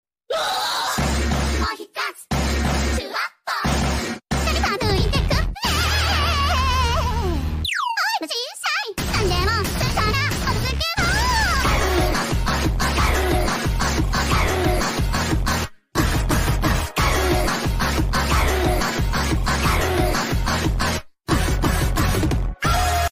Grr Puff puff
Grr-Puff-puff.mp3